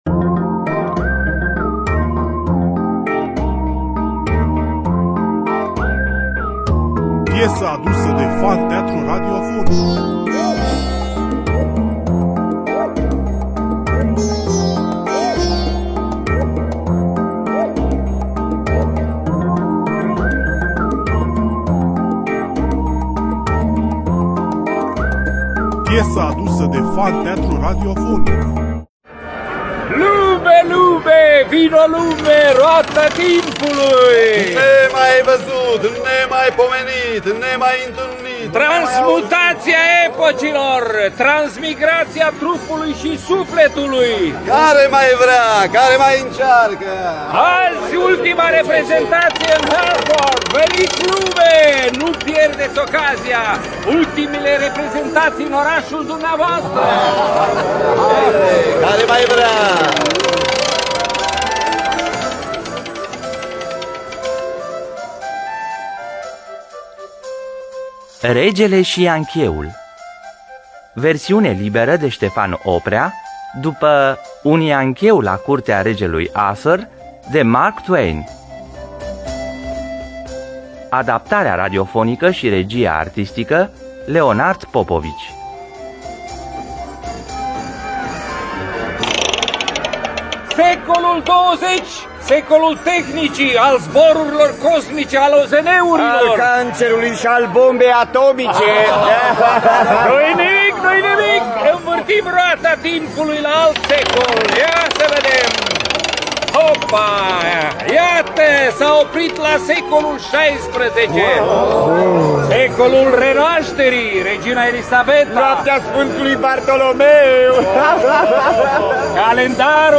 Stefan Oprea – Regele Si Yankeul (1999) – Teatru Radiofonic Online